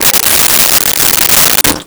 Sink 05
Sink 05.wav